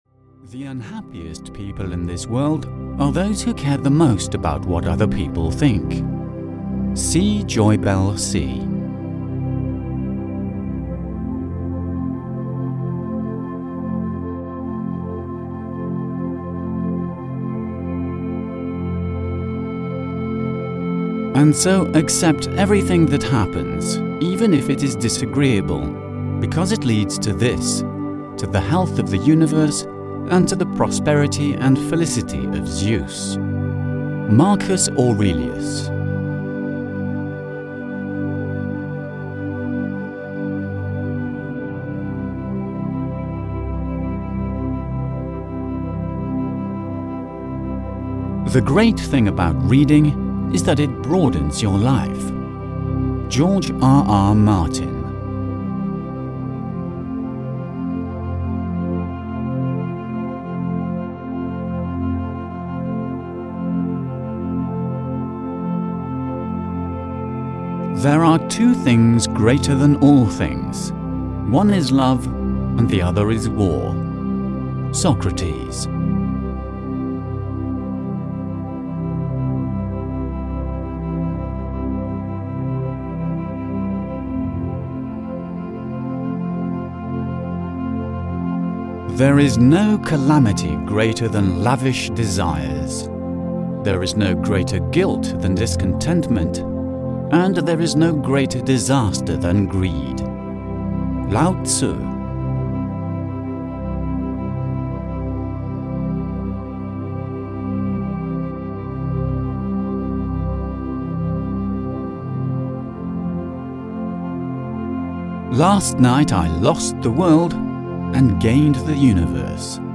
100 Inspirational Quotes (EN) audiokniha
Ukázka z knihy